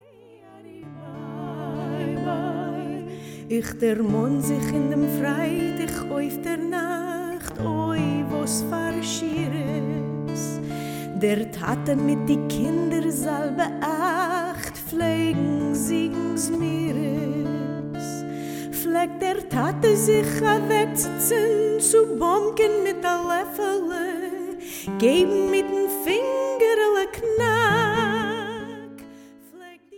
Featuring studio recordings of folk
Folk